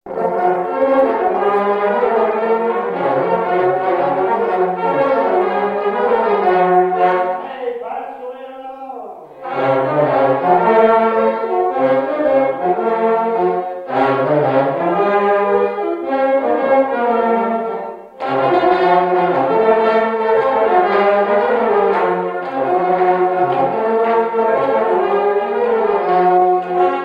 Mareuil-sur-Lay
danse : quadrille : pastourelle
Pièce musicale inédite